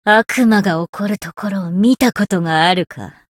灵魂潮汐-萨缇娅-互动-不耐烦的反馈2.ogg